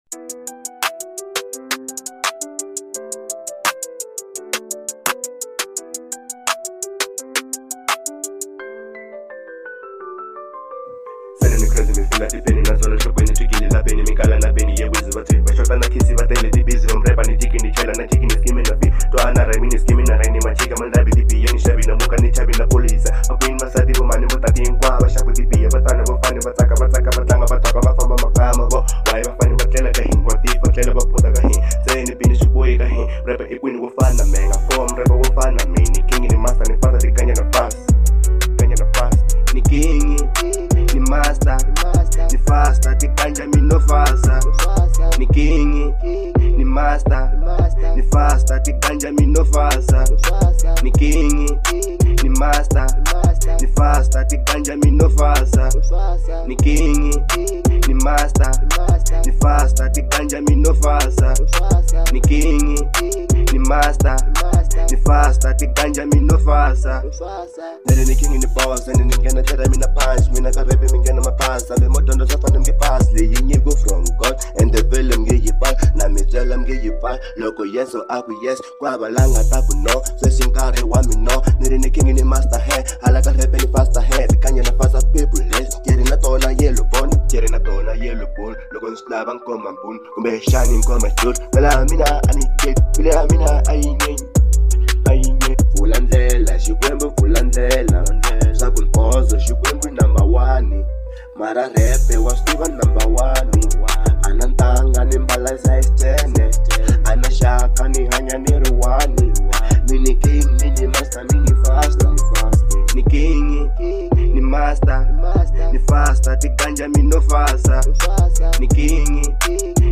03:00 Genre : Hip Hop Size